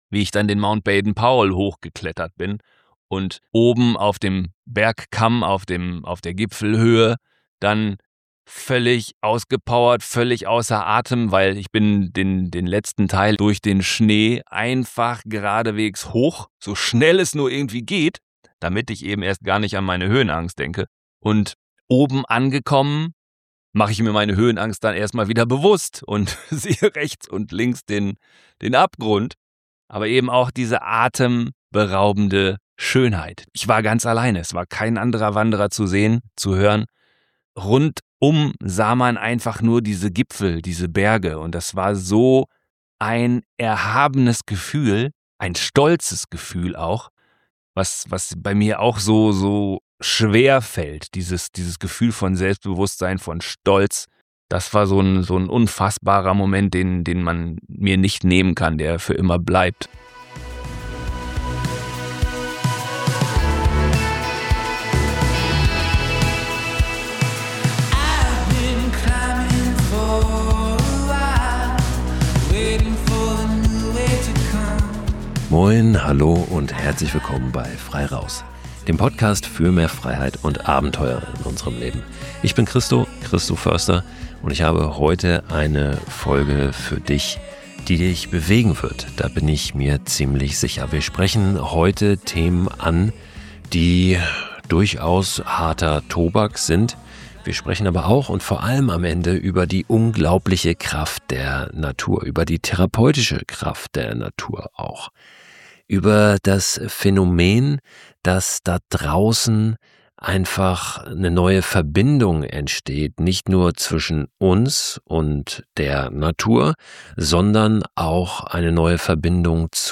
Deshalb hatten wir auch ein besonderes, etwas experimentelles Aufnahme-Setting. Entstanden ist ein schonungslos ehrliches Gespräch über Schmerz, Scham und die langen Schatten traumatischer Erfahrungen – aber auch eines über Hoffnung, Selbstbegegnung und die unglaubliche Kraft der Natur ...